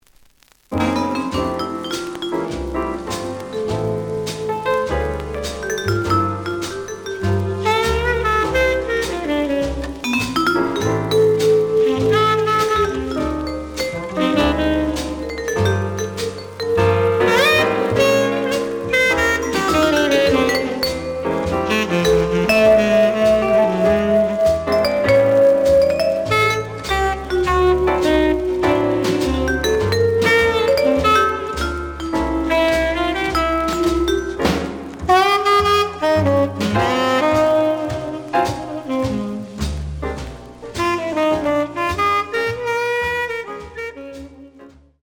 The audio sample is recorded from the actual item.
●Genre: Latin Jazz